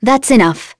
Glenwys-Vox_Skill2.wav